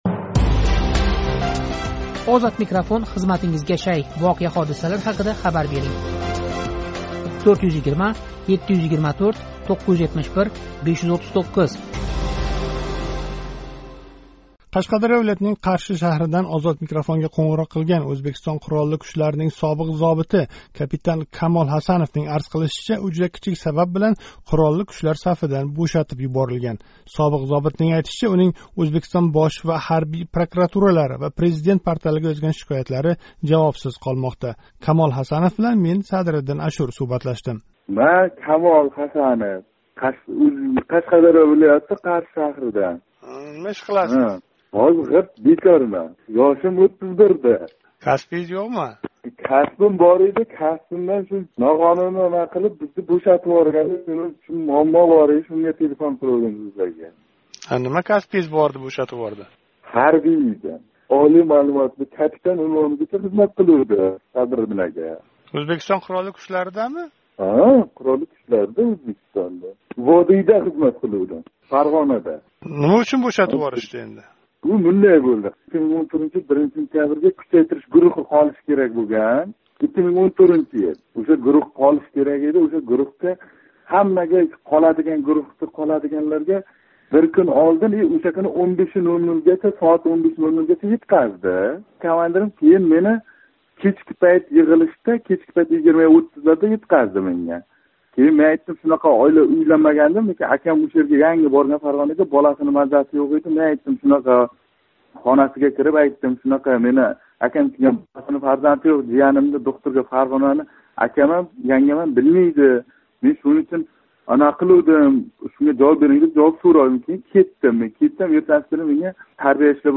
Қашқадарё вилоятининг Қарши шаҳридан OzodMikrofonга қўнғироқ қилган Ўзбекистон Қуролли кучларининг собиқ зобити